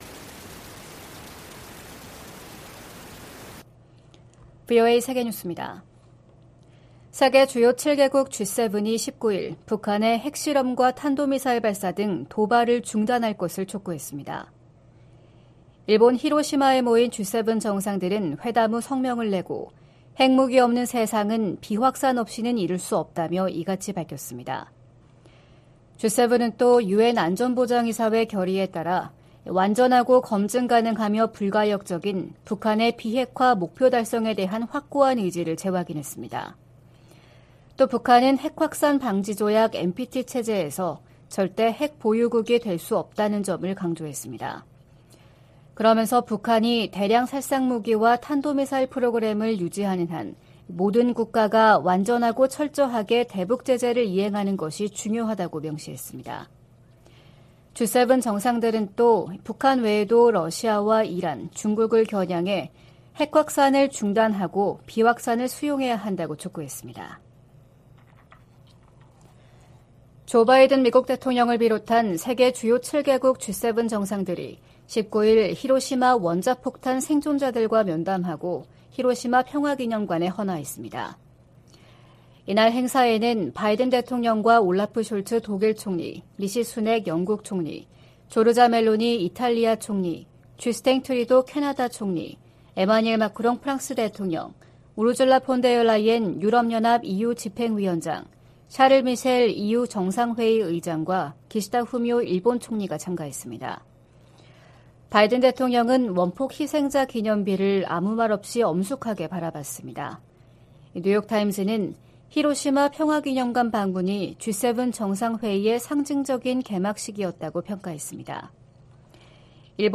VOA 한국어 '출발 뉴스 쇼', 2023년 5월 20일 방송입니다. 미국과 일본 정상이 히로시마에서 회담하고 북한의 핵과 미사일 문제 등 국제 현안을 논의했습니다. 윤석열 한국 대통령이 19일 일본 히로시마에 도착해 주요7개국(G7) 정상회의 참가 일정을 시작했습니다. 북한이 동창리 서해발사장에 새로짓고 있는 발사대에서 고체연료 로켓을 시험발사할 가능성이 높다고 미국 전문가가 분석했습니다.